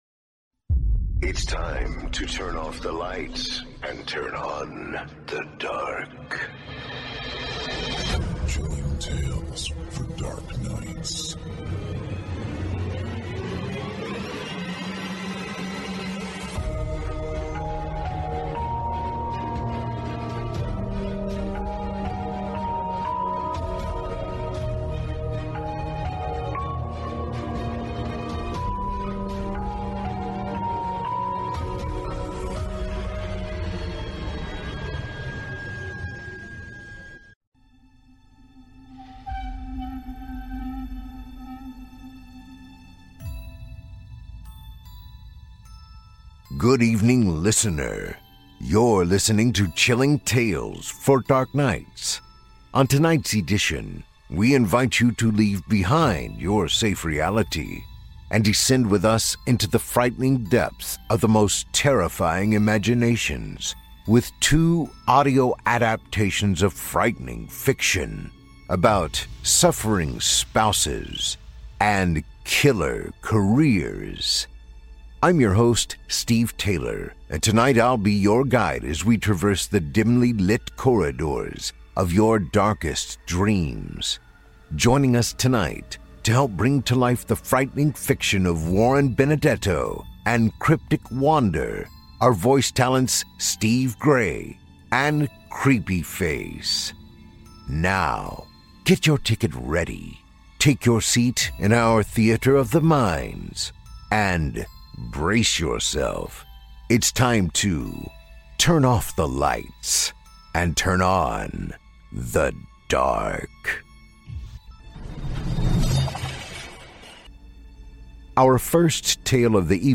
On tonight’s edition, we invite you to leave behind your safe reality, and descend with us into the frightening depths of the most terrifying imaginations, with two audio adaptations of frightening fiction, about suffering spouses and killer careers.